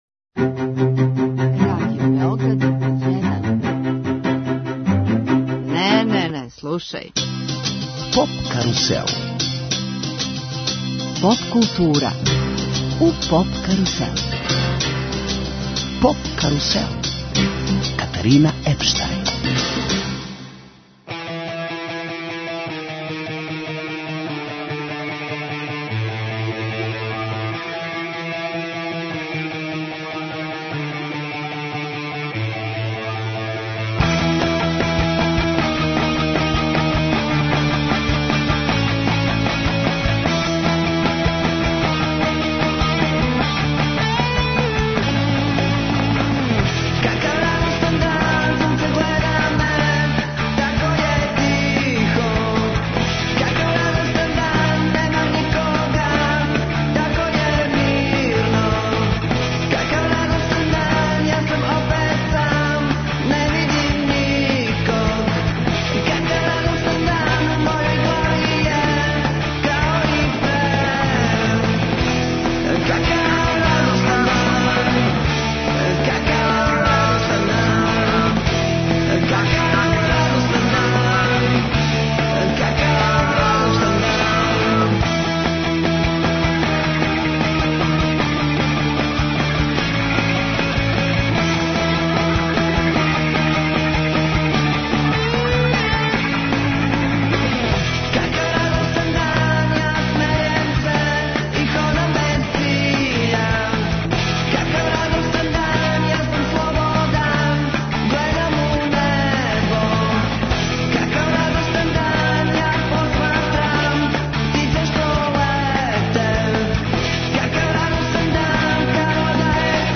Као гости града Ниша, узвратићемо гостопримство дивним уметницима и организацијама, овог лепог града, разговарајући о свему што Ниш чини посебним.